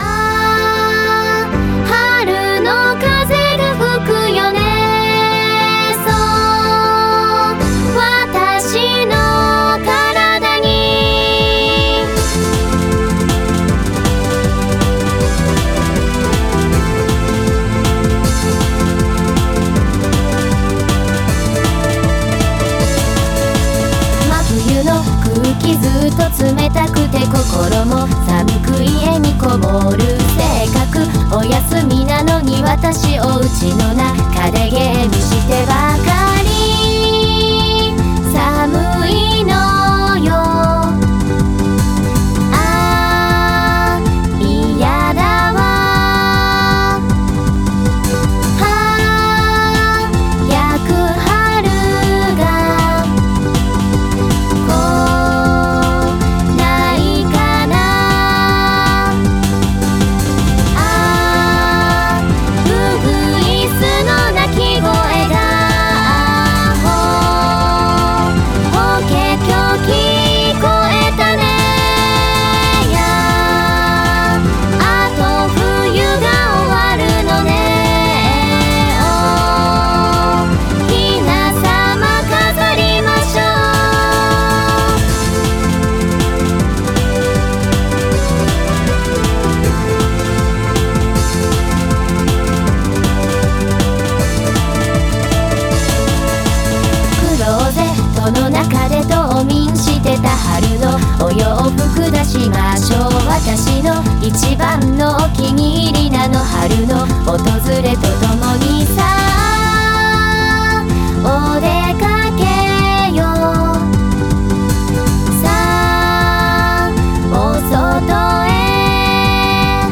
近年の合成音声はクオリティが高く、まるで人間のように自然な声で歌います。
Vocal Synthesizer
春をモチーフにした女の子2人の歌です。